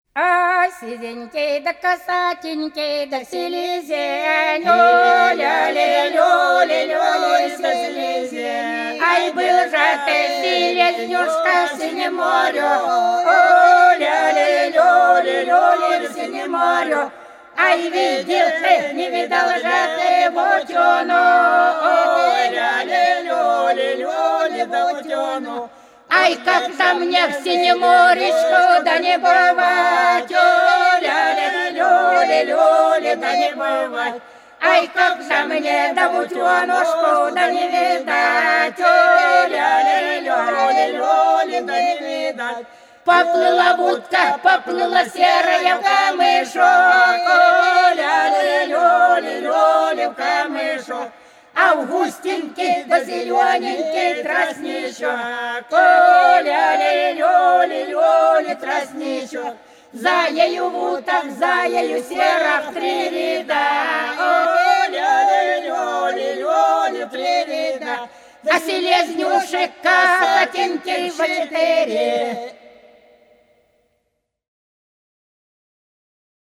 По-над садом, садом дорожка лежала Сизенький да касательный селезень - свадебная (с.Плёхово, Курская область)